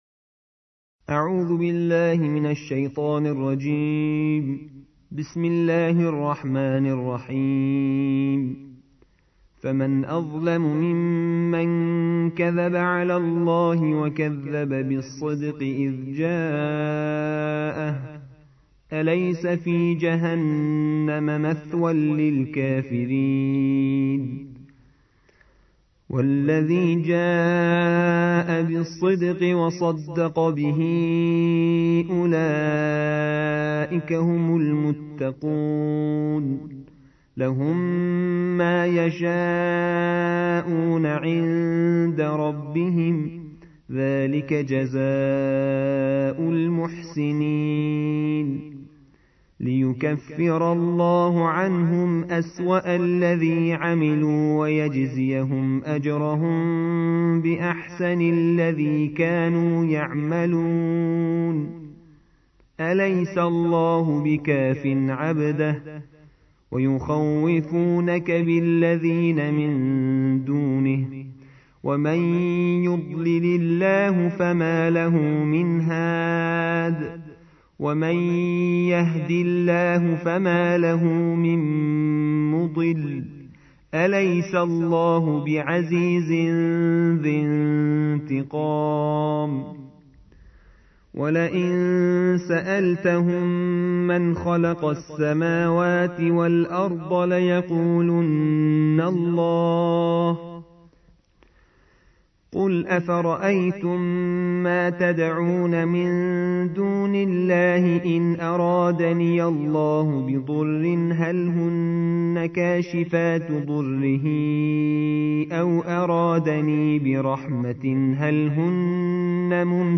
الجزء الرابع والعشرون / القارئ